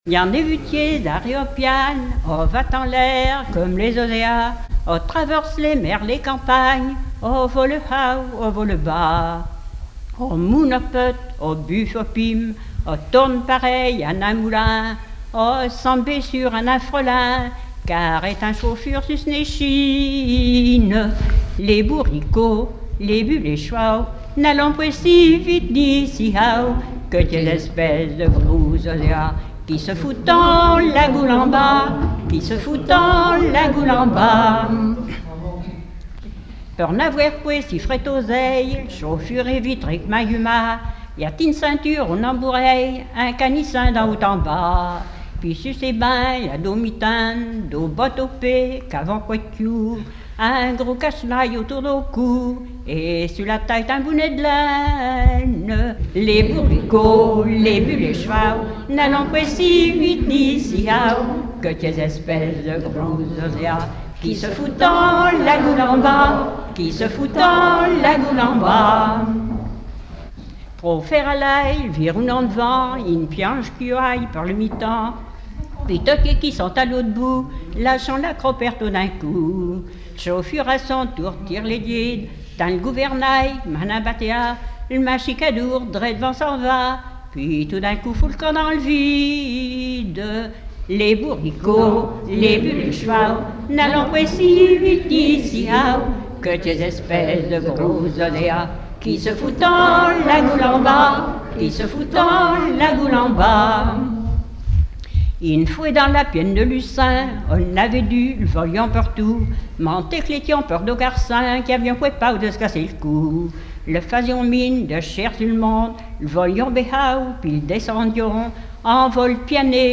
Regroupement au foyer logement
Catégorie Pièce musicale inédite